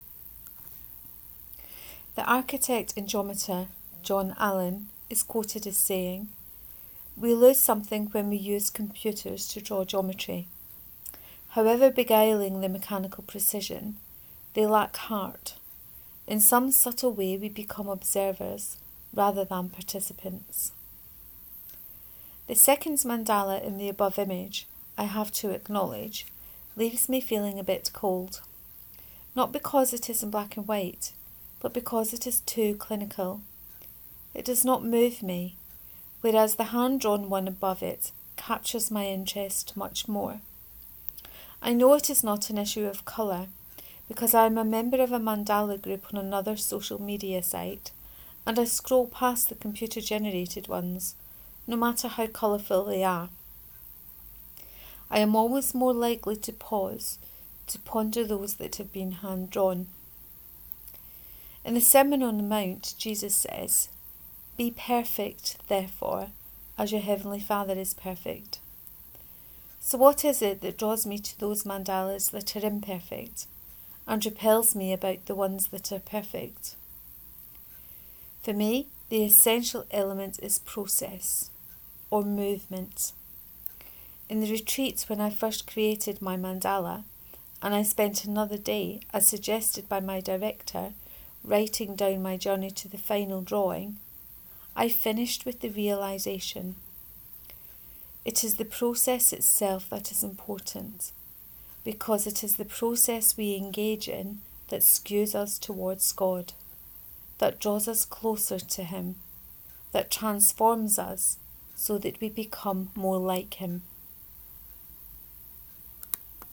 The Perfect Imperfect 2: Reading of this post.